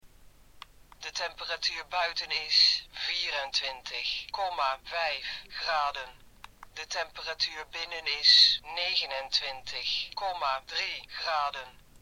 MyDegrees sprekende thermometer
Het geluidsfragment hieronder toont hoe de MyDegrees klinkt: